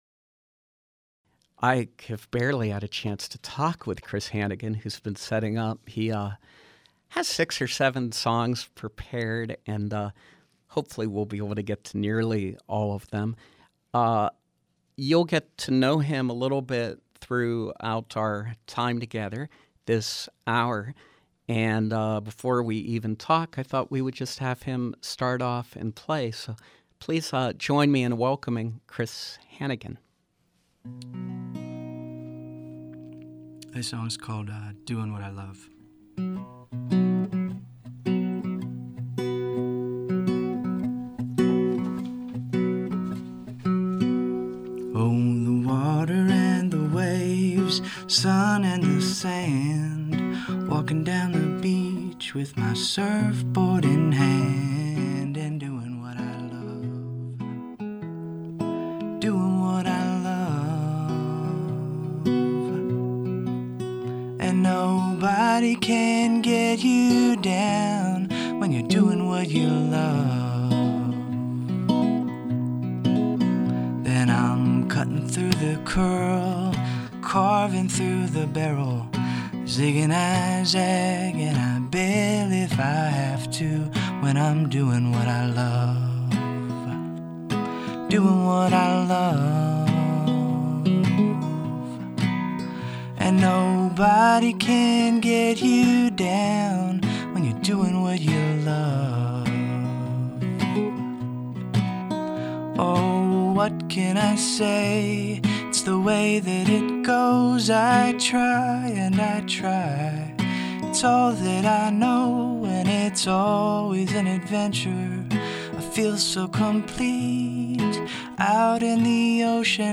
Singer-songwriter
performing live